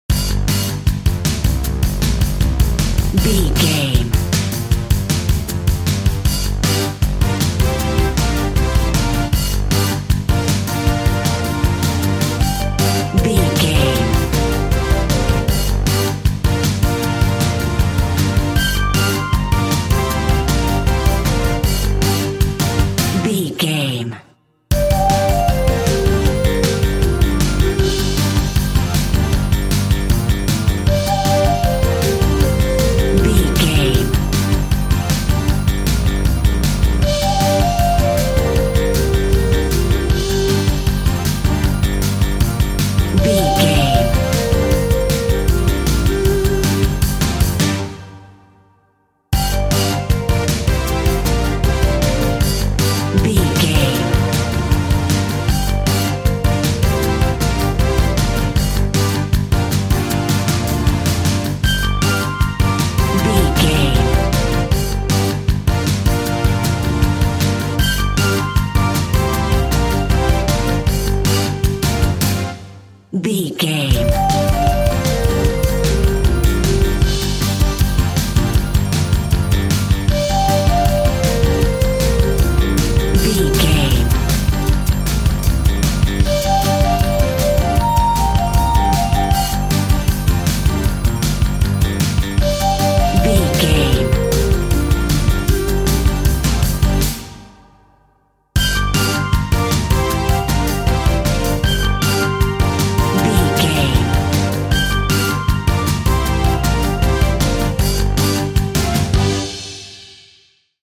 Aeolian/Minor
Fast
chaotic
driving
energetic
bass guitar
synthesiser
percussion
electric piano